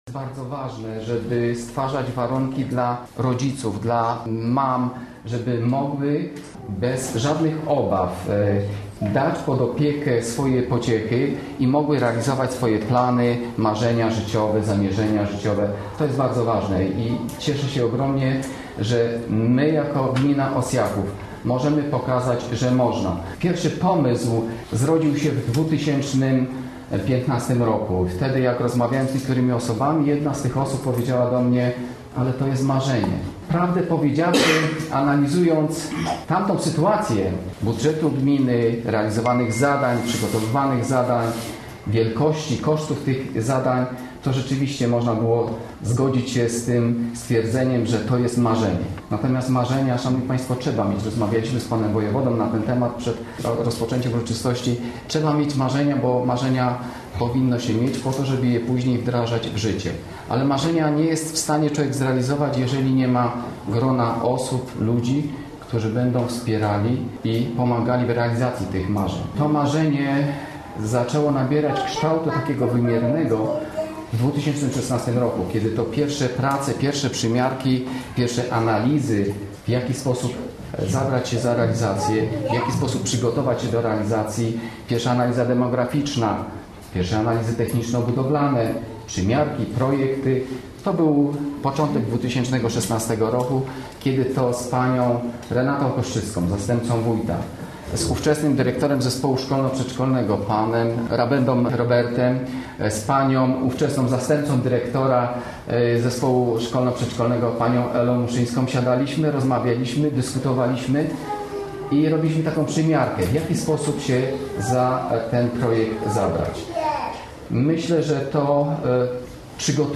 Nowoczesnym obiektem cieszy się już 25 najmłodszych dzieci w wieku do trzech lat. Dziś odbyło się oficjalne i uroczyste otwarcie długo wyczekiwanej placówki.